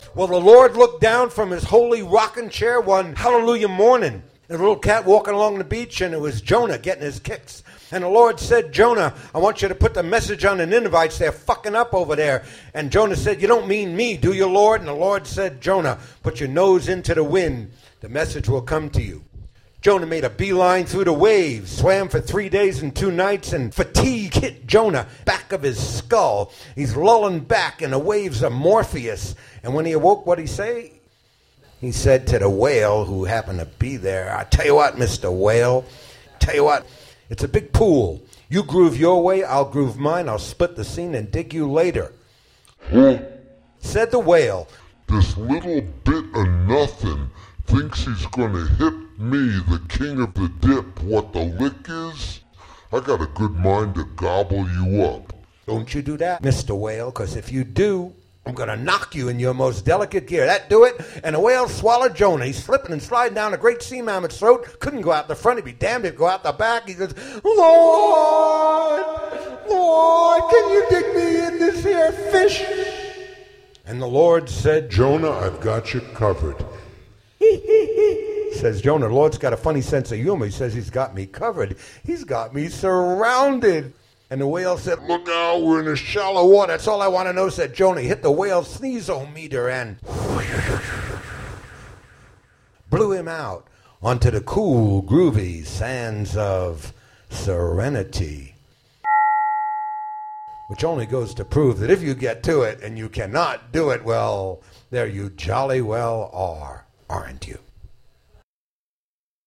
Mercury Café Open Mic Nite